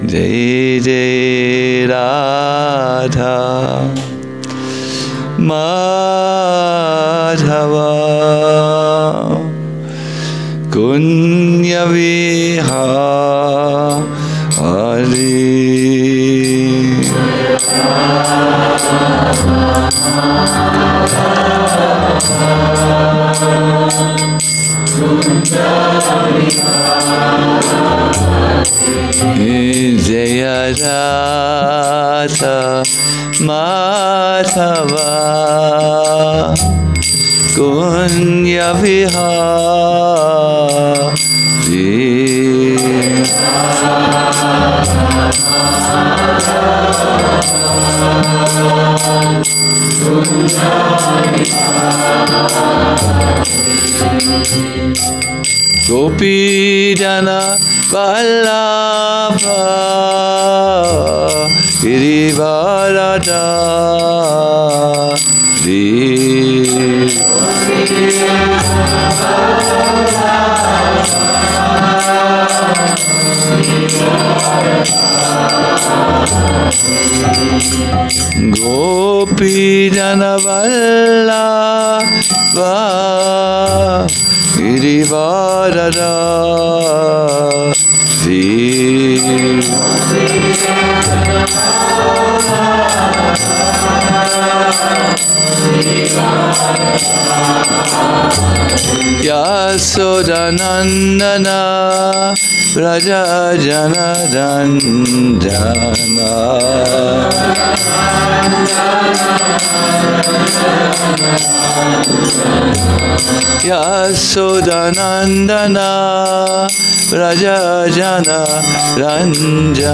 Cc. An 4.71 The Secret to Absorbed Chanting - a lecture